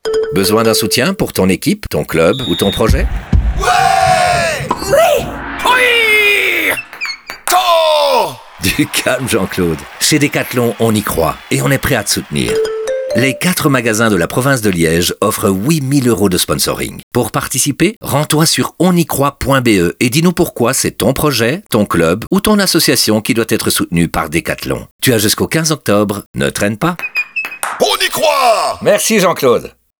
DES PUBS AUDIOS
Plus qu’un spot avec une bande sonore ordinaire, nous créons un univers sonore unique et distinct pour chaque spot avec des effets, des bruitages immersifs et une musique adaptée à votre production.